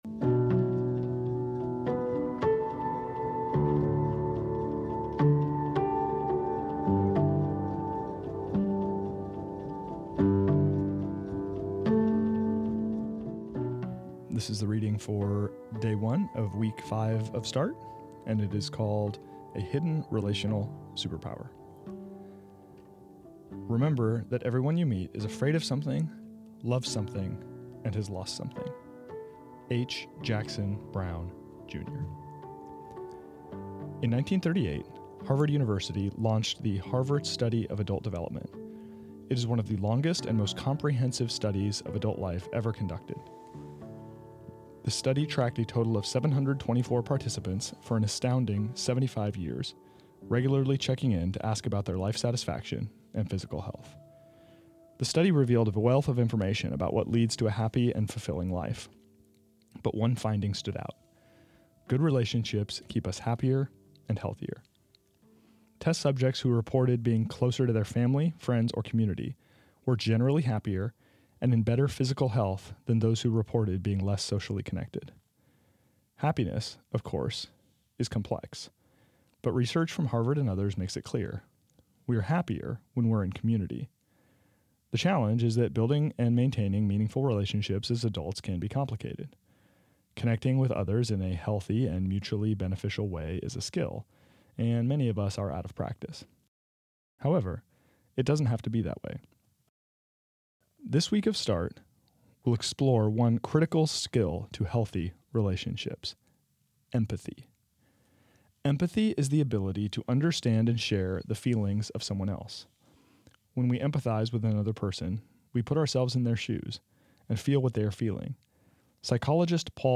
This is the audio recording of the first reading of week seven of Start, entitled A Hidden Relational Superpower.